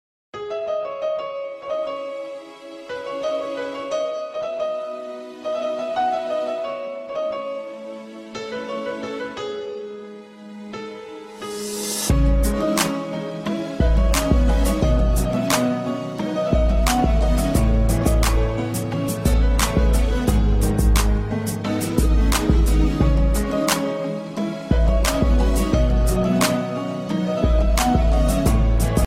ژانر: بی کلام
اهنگ زنگ رمانتیک ترکی